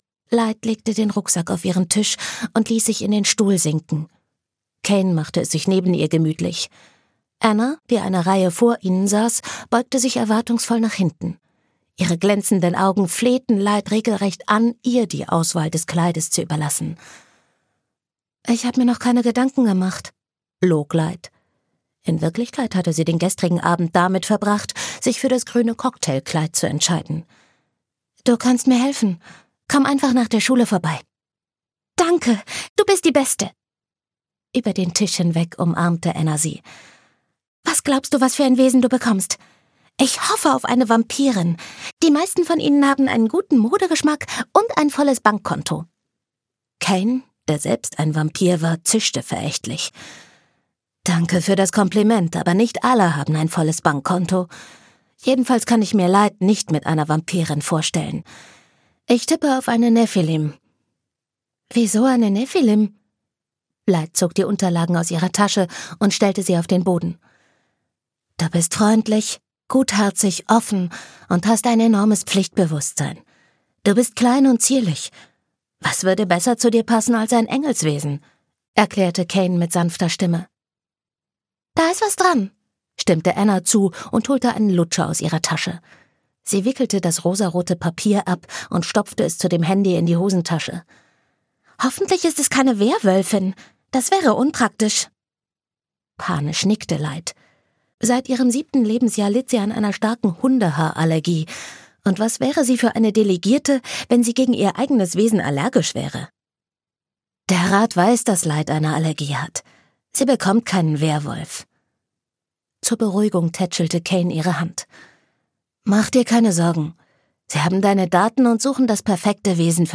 Hörbuch Light & Darkness, Laura Kneidl.